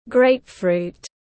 Grapefruit /’greipfru:t/